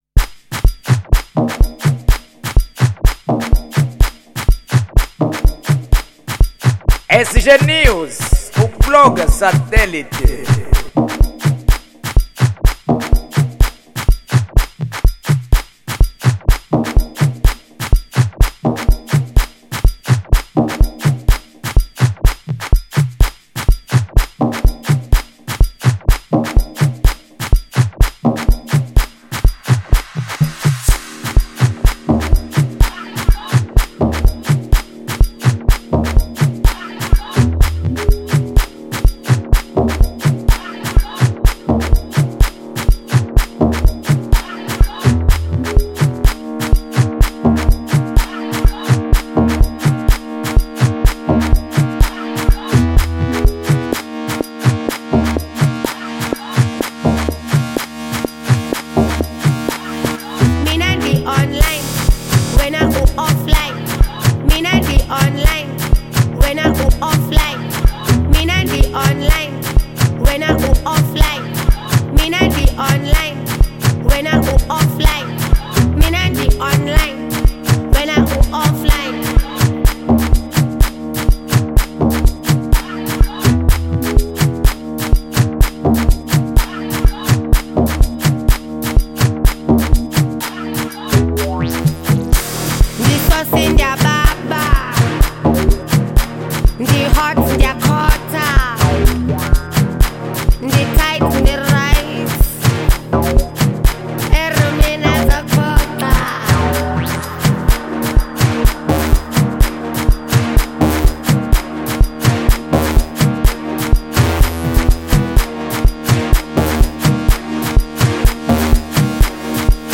Género : Afro House